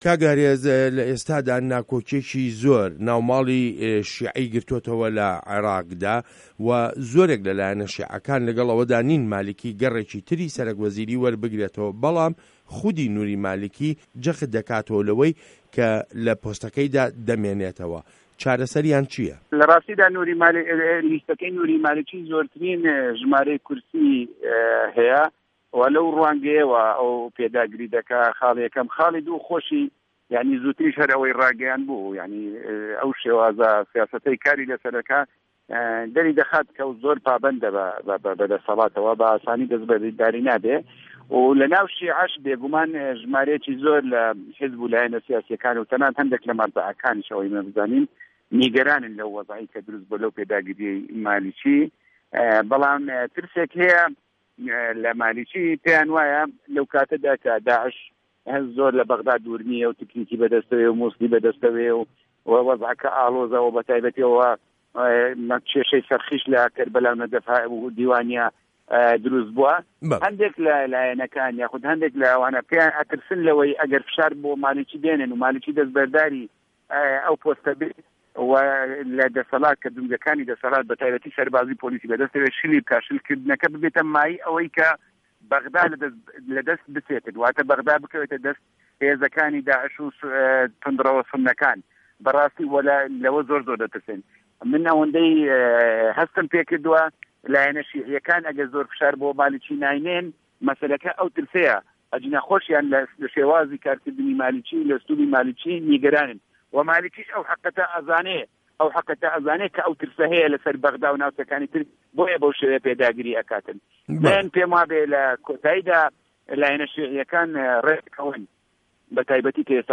وتووێژ له‌گه‌ڵ ئارێز عه‌بدوڵا